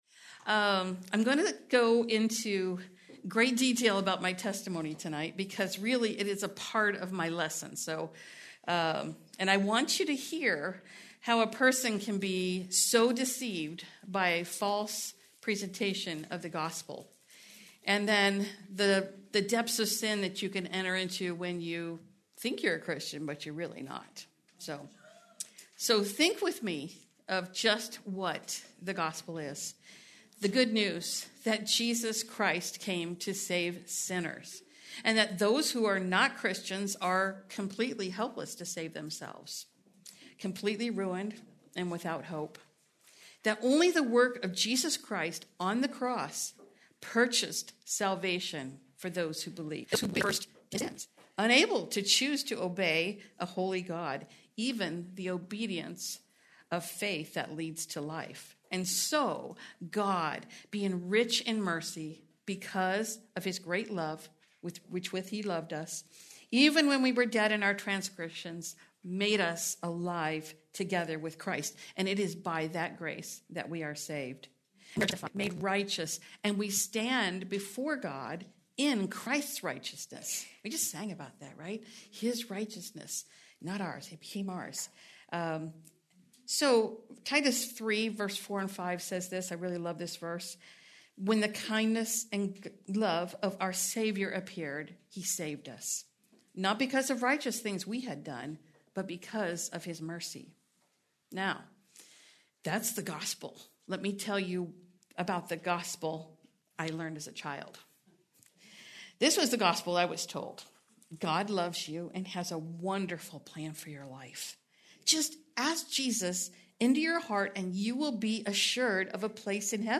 Please note, due to technical difficulties, this recording skips brief portions of audio.